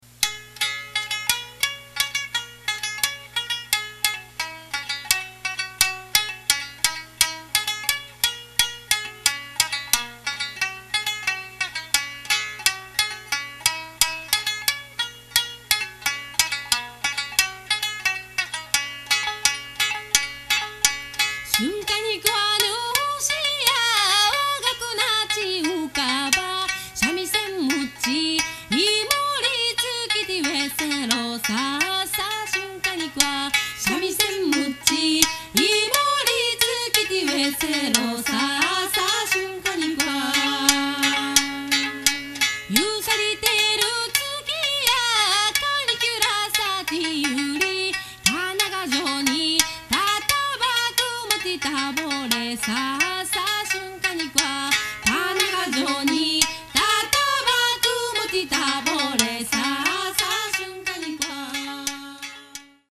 太鼓・指笛